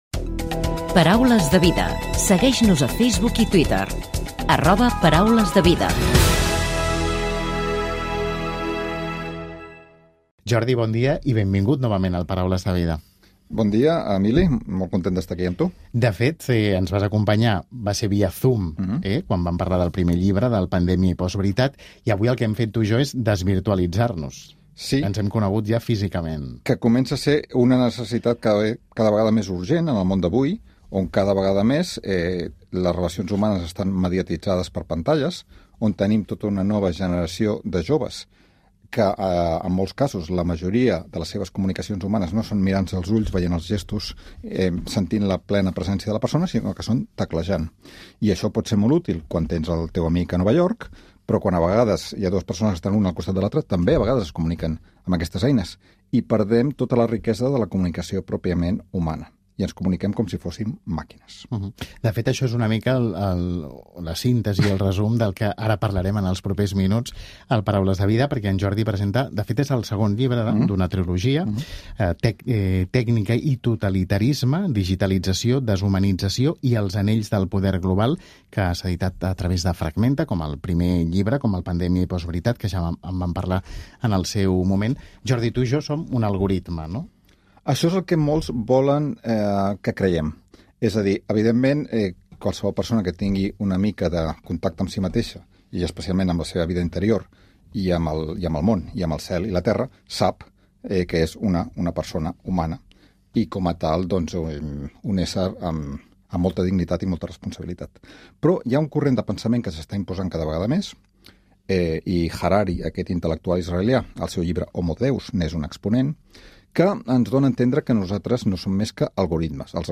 Indicatiu del programa, entrevista al filòsof
Gènere radiofònic Religió